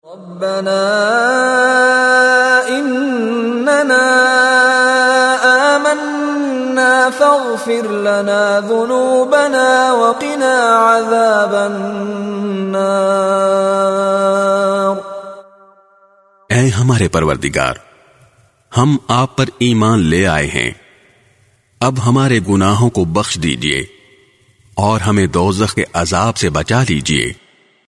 by Mishary Rashid Alafasy
His melodious voice and impeccable tajweed are perfect for any student of Quran looking to learn the correct recitation of the holy book.
Rabbana innana amanna faghfir lana - full dua with translation.mp3